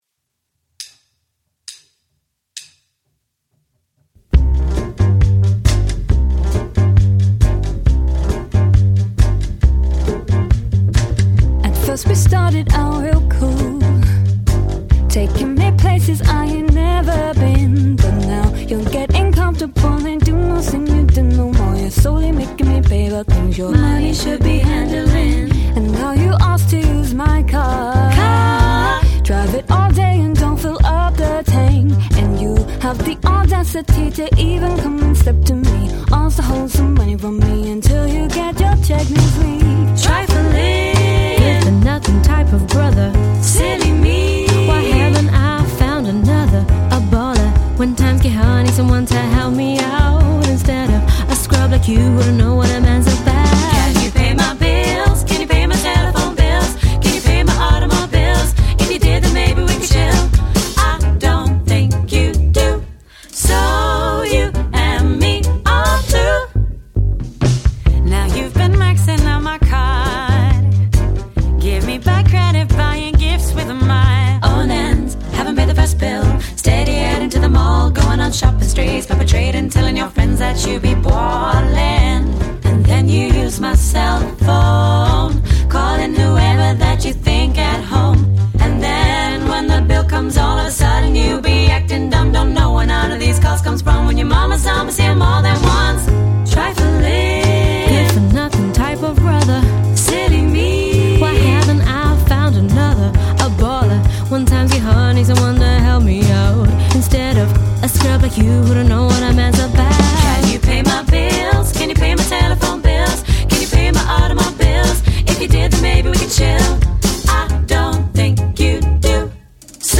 • Unique vintage twists on popular songs
Three Female Vocal Harmony Speakeasy Swing Band for Hire